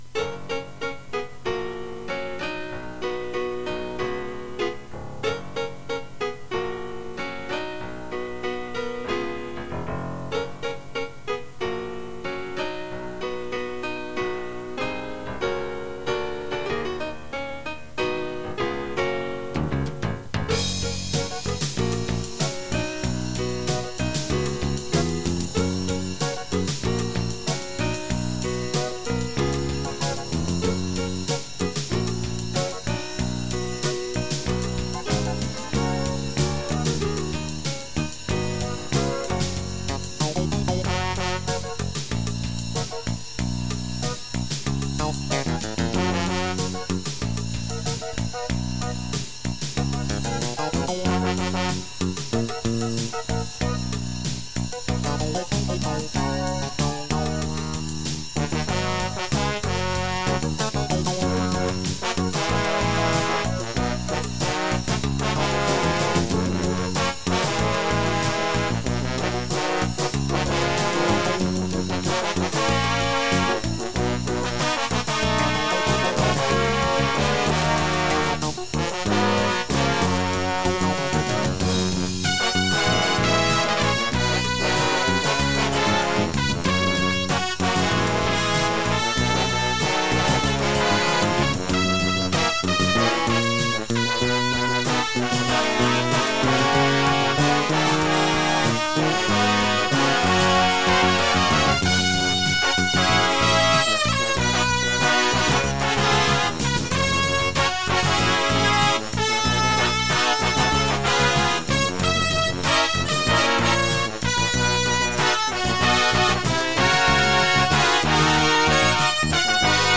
MORE TO PLAY-ALONG WITH